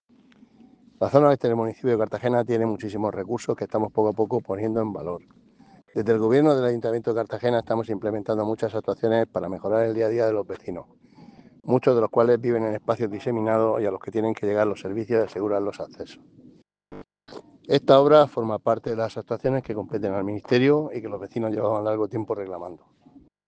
Declaraciones de José Ramón Llorca.